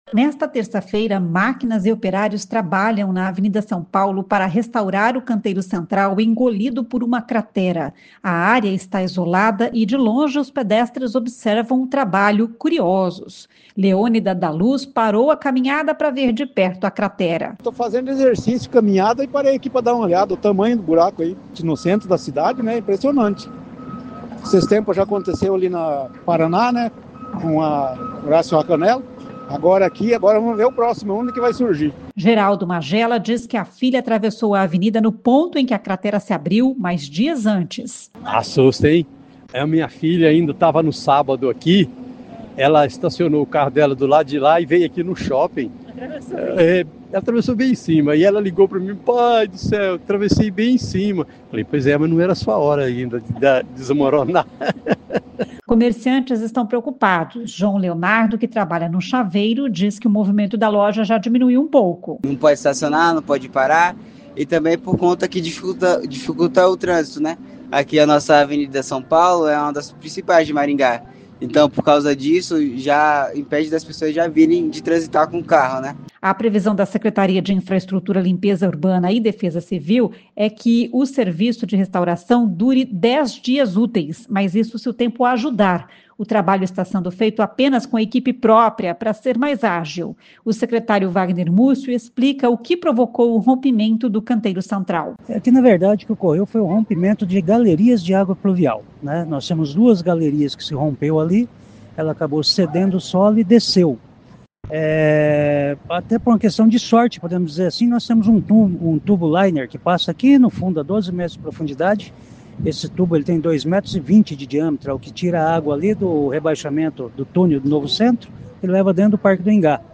O secretário Vagner Mussio explica o que provocou o rompimento do canteiro central.